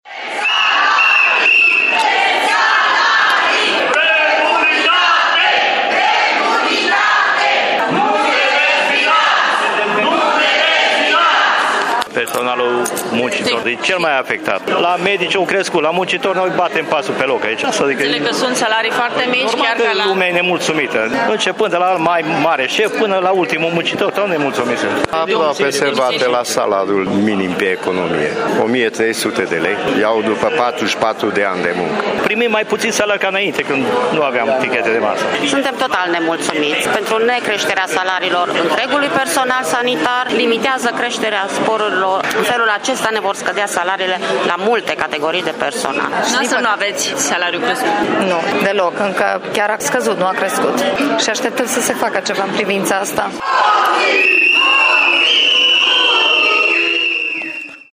Greva de avertisment de două ore din această dimineață a scos pe holurile Spitalului Clinic Județean de Urgență din Tîrgu-Mureș câteva sute sindicaliști, în special muncitori, personal TESSA, infirmiere, brancardieri, dar și asistente, toți nmulțumiți de salariile prea mici sau de tăierea sporurilor: